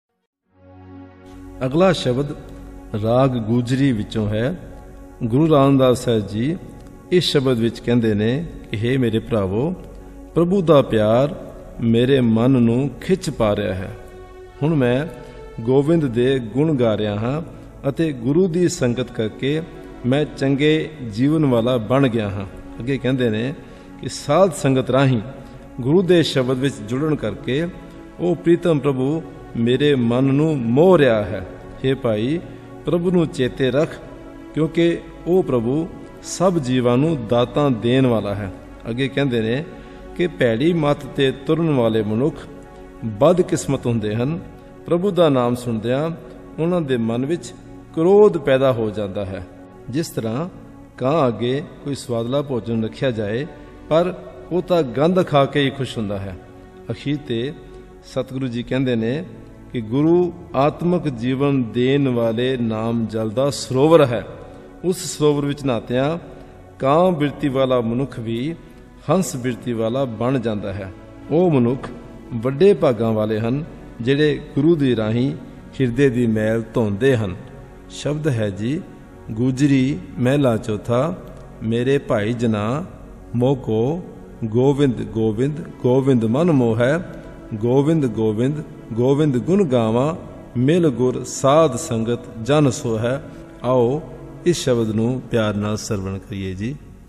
This entry was posted in Shabad Kirtan and tagged , , , , .